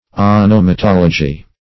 Search Result for " onomatology" : The Collaborative International Dictionary of English v.0.48: Onomatology \On`o*ma*tol"o*gy\, n. [Gr.
onomatology.mp3